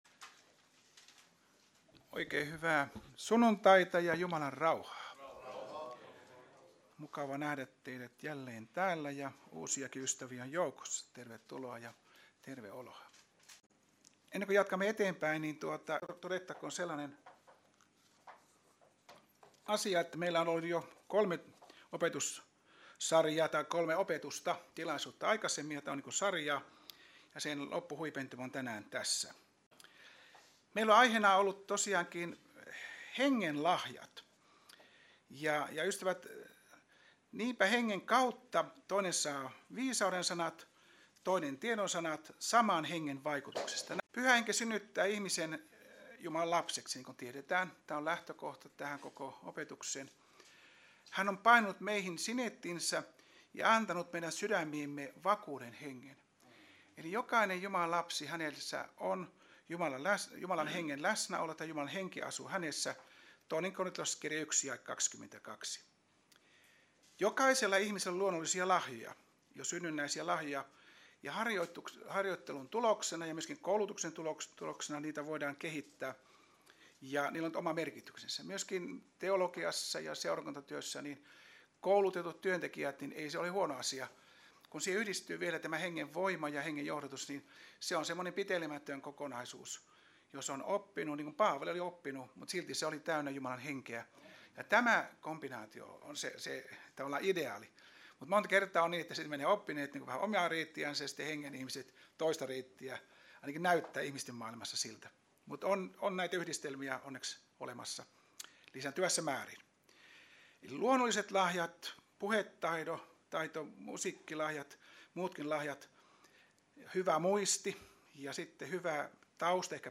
Raamattutunti